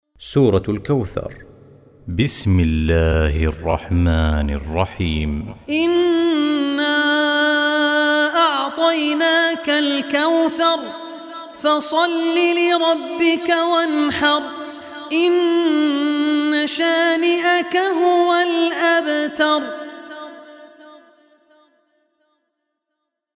Surah Kausar Recitation by Abdur Rehman Al Ossi
Surah Kausar, listen online mp3 tilawat / recitation in the voice of Sheikh Abdur Rehman Al Ossi.